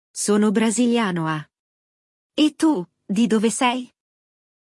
No diálogo, você ouvirá uma pessoa perguntando se a outra é italiana.